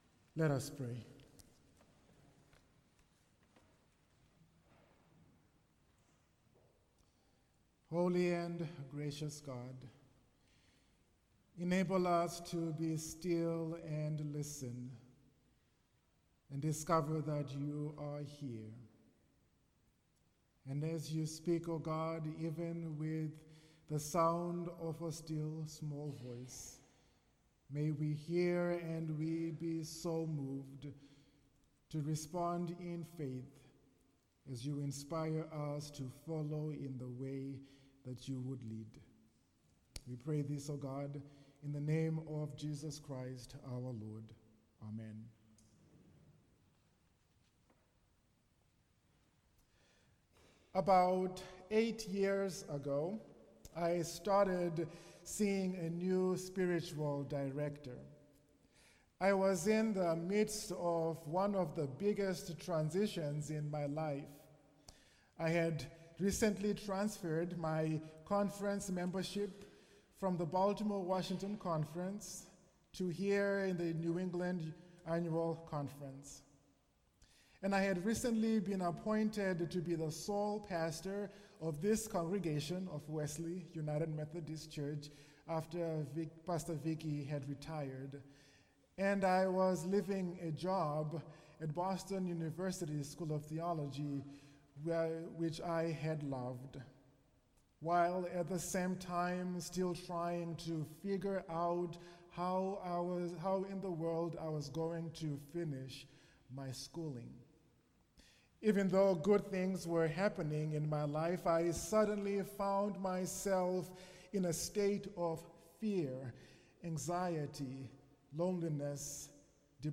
Seeking God in troubled Times – Wesley United Methodist Church, Worcester, Massachusetts